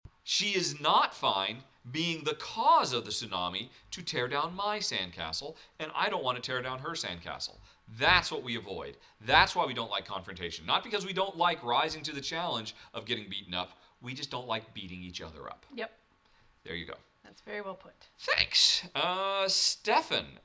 I just made a 3 hour recording today, and about half way through, one of the lapel mics suddenly “went bad” and started generating a terrible hum which I didn’t hear until it was all over.